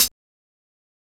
Closed Hats
HiHat (18).wav